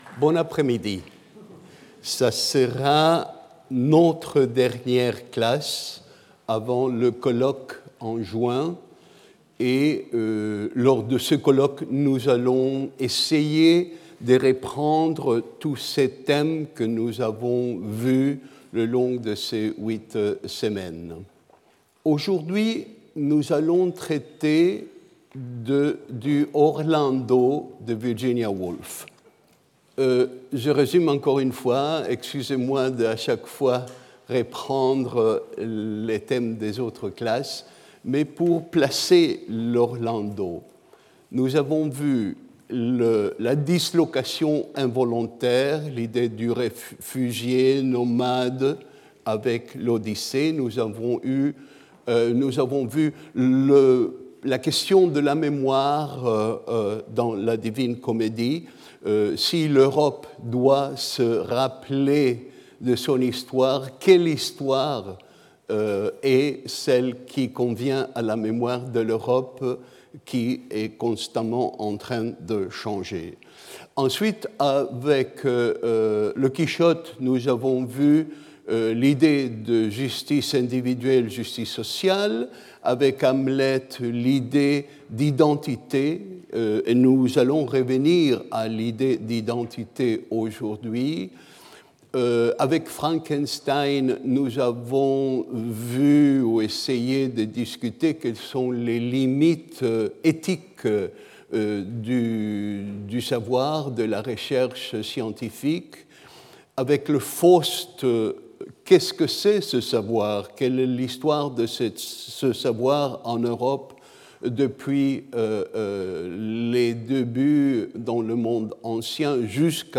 Alberto Manguel Professeur invité, Collège de France
Cours